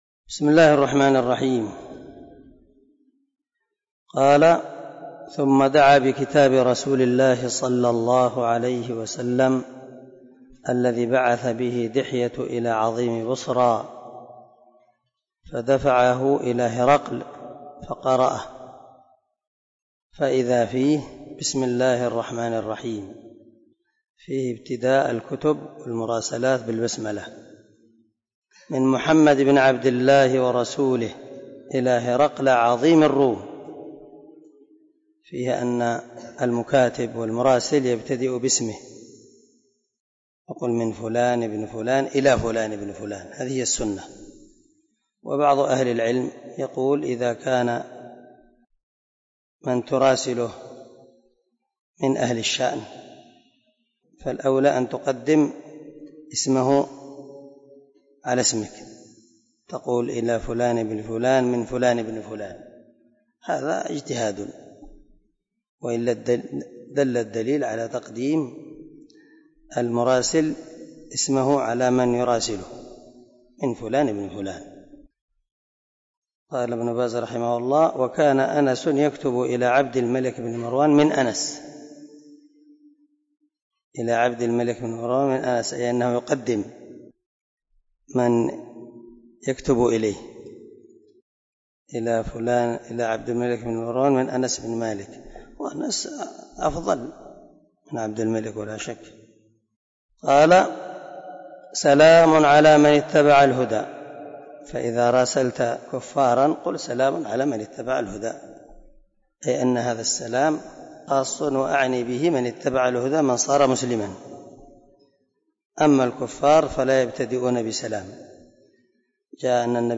010الدرس 8 من كتاب بدء الوحي حديث رقم ( 8 ) من صحيح البخاري